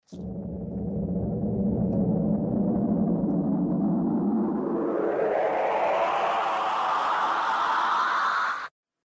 Звук появления волшебного джина